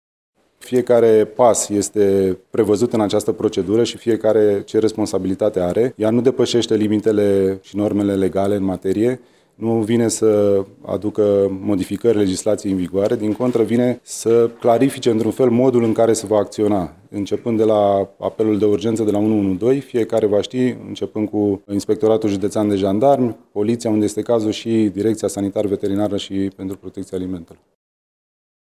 Prefectul Cătălin Văsii: